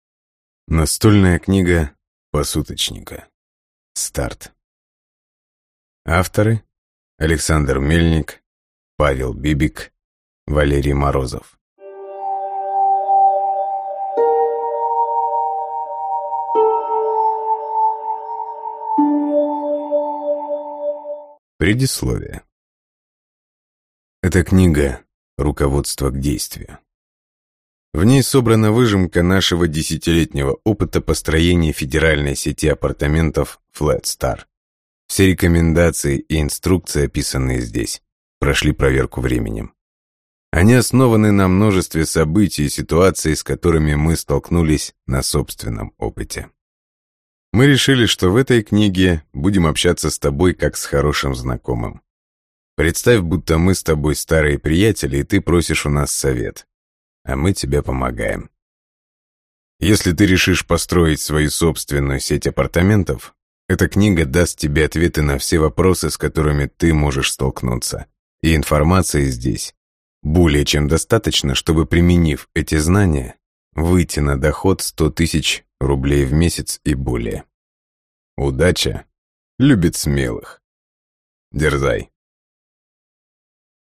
Аудиокнига Настольная книга посуточника. Старт | Библиотека аудиокниг